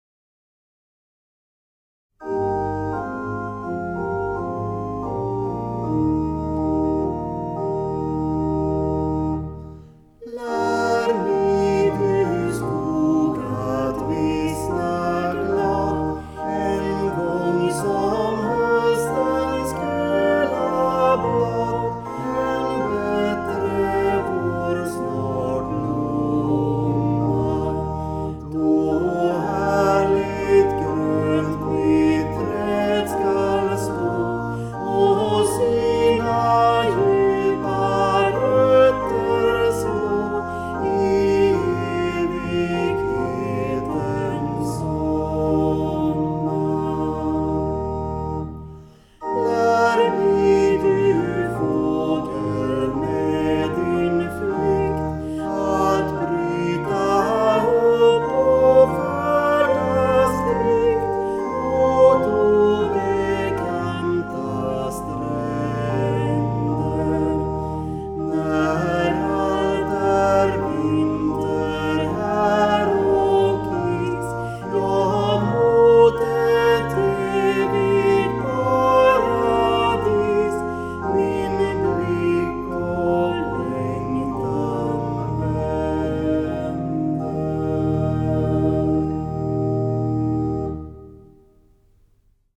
Psalmer vid begravning
Här kan du lyssna på ett urval av psalmer som församlingens musiker spelar.